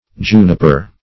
Juniper - definition of Juniper - synonyms, pronunciation, spelling from Free Dictionary
Juniper \Ju"ni*per\, n. [L. juniperus, prop., youth-producing,